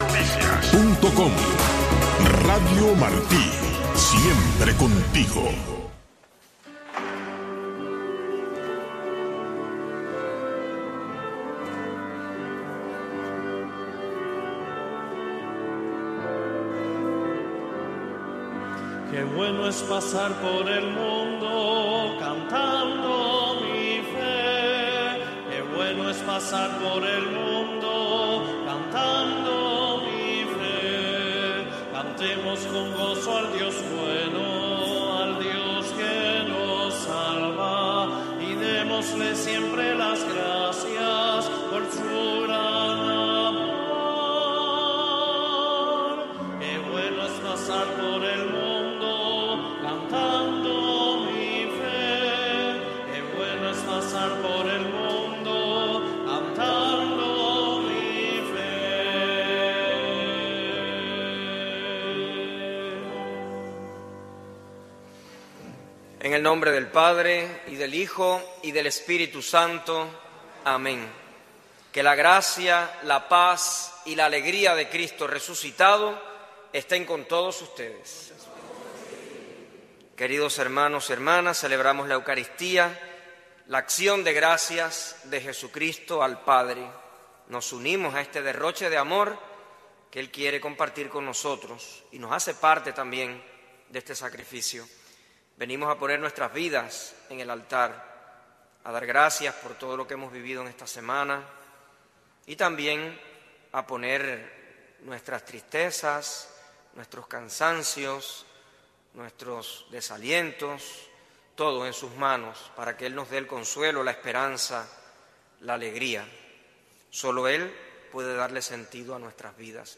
La misa dominical transmitida para Cuba desde el Santuario Nacional de Nuestra Señor de la Caridad, un templo católico de la Arquidiócesis de Miami dedicado a la Patrona de Cuba.